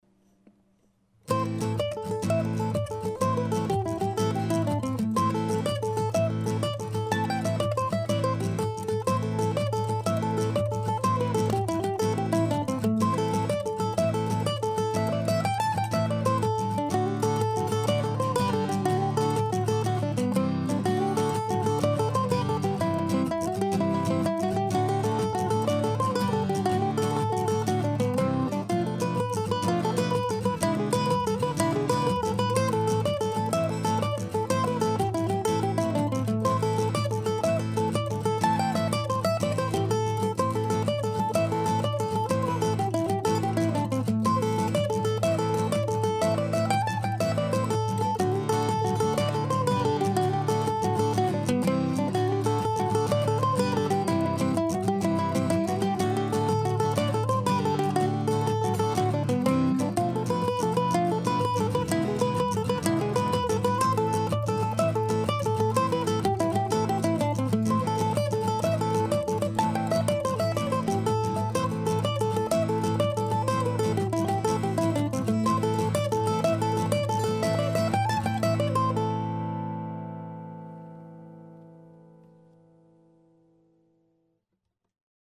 The recording is old enough now that I can't tell what guitar I'm playing but I suspect the mandolin is my faithful old Gibson A.
This tune is a bit unusual in having a half-diminished chord in the harmony of the B section.